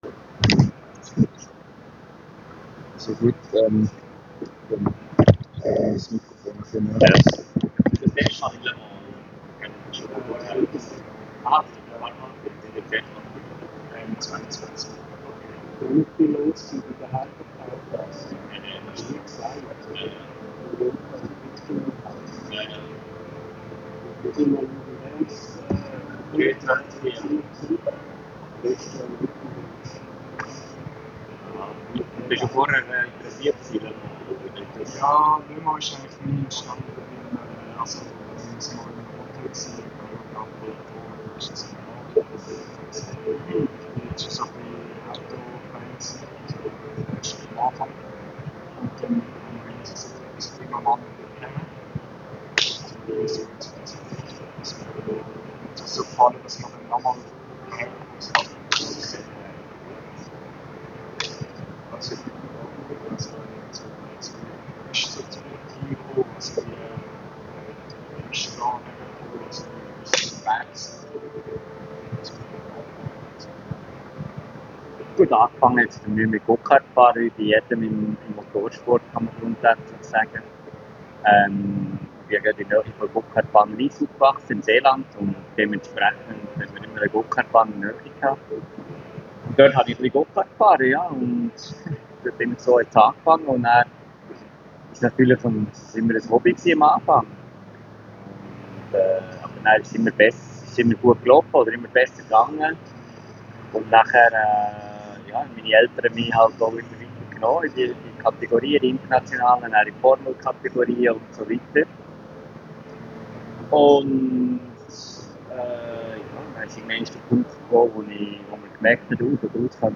Für die Audiodatei mit dem Interview mit Neel Jani klicken Sie hier (Schweizerdeutsch)